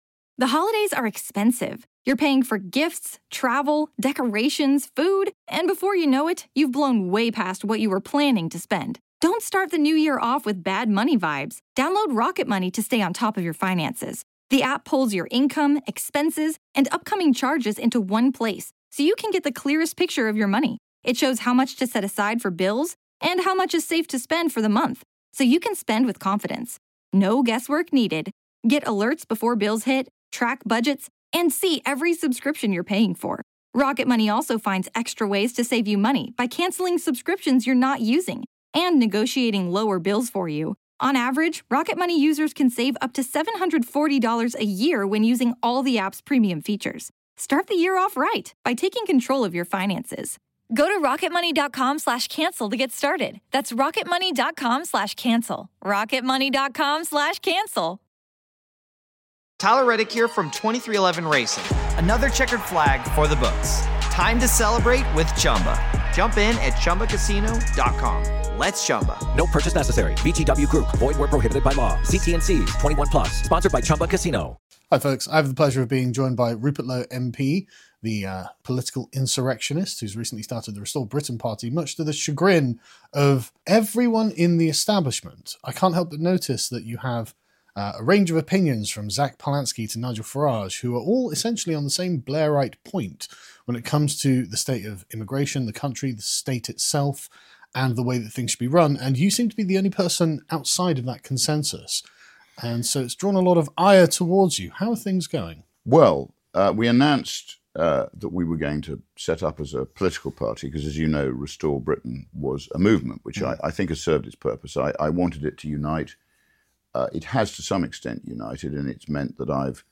"Spread the Word" | Interview with Rupert Lowe MP